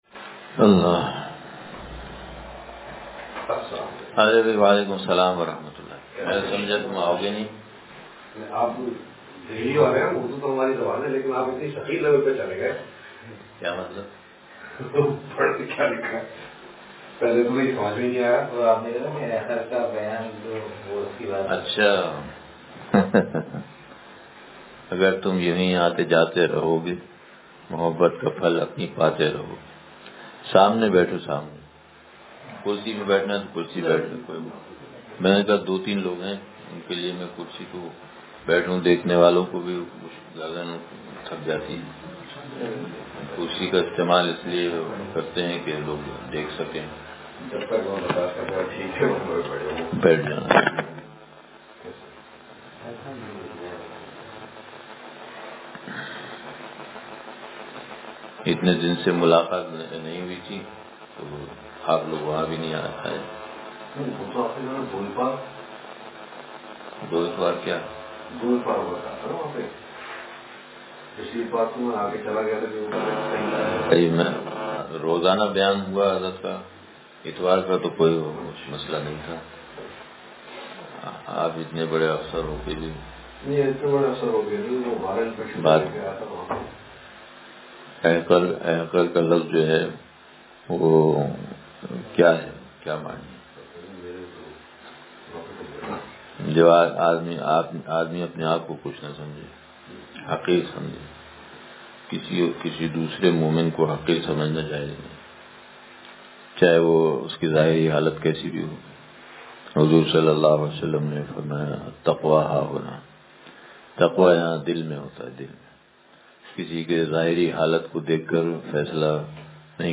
قلبِ مضطر – بیان